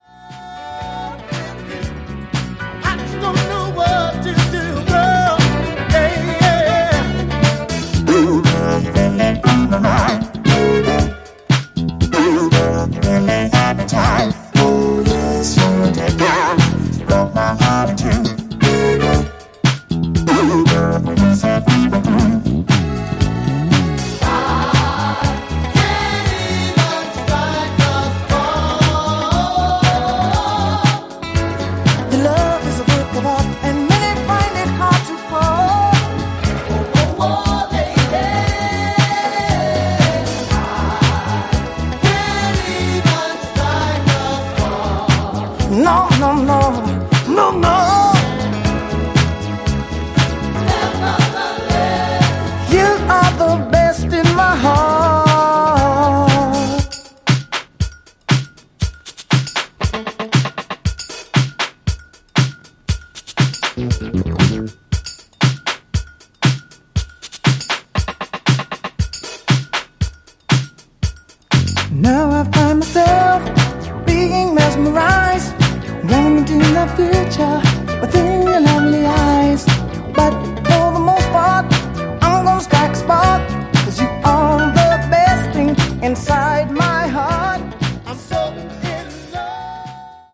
a San Francisco based boogie funk band